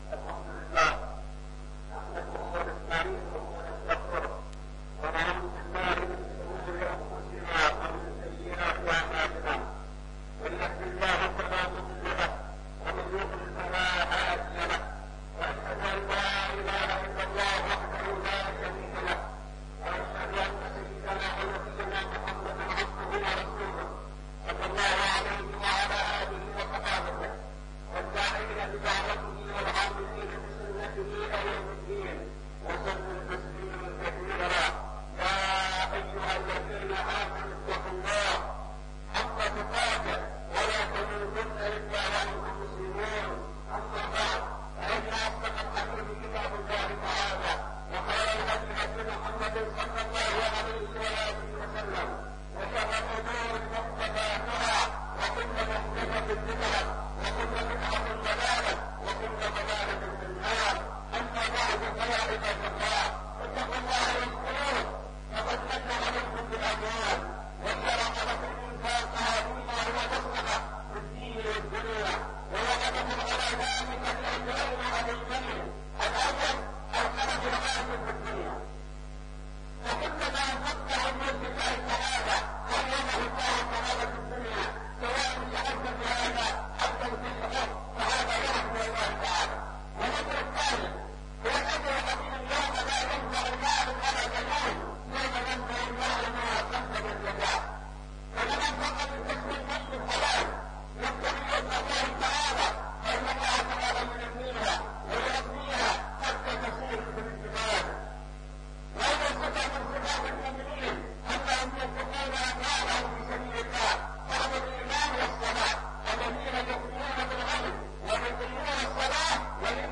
(خطبة جمعة) بعنوان